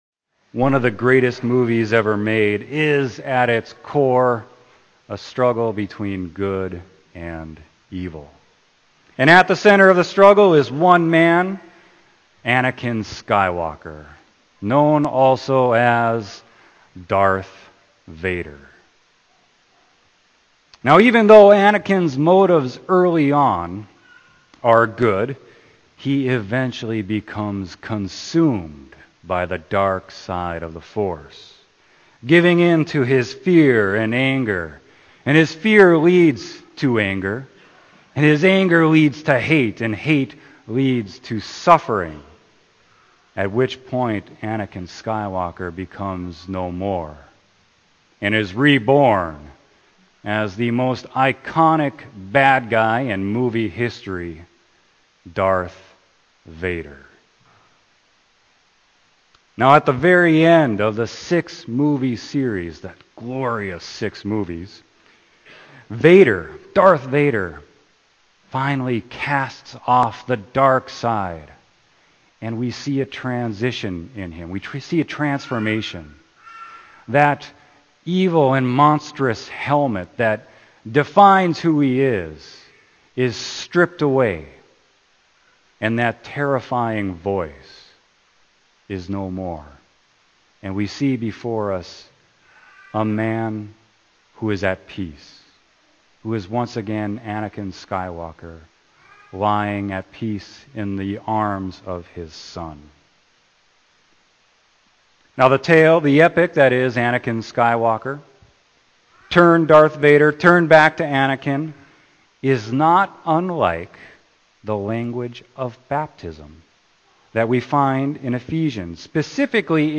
Sermon: Ephesians 4.25-5.2